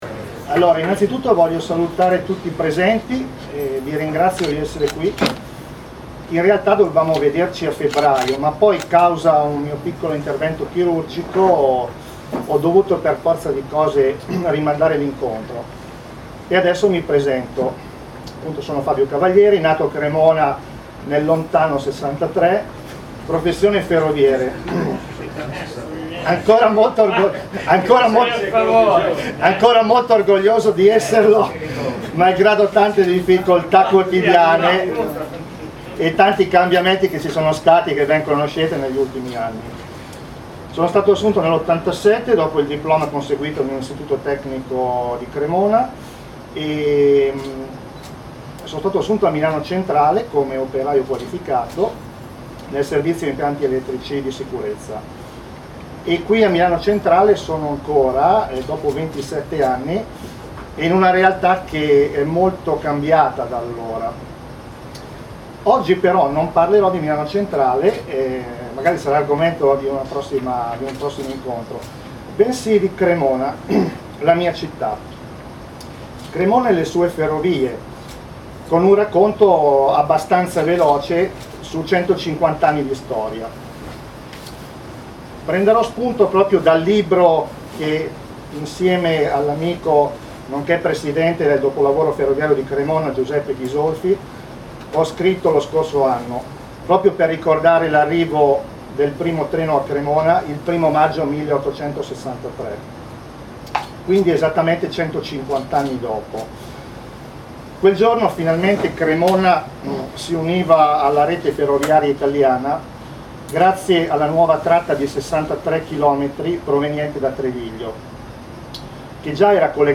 È disponibile la registrazioni audio della conferenza del 20 giugno 2014: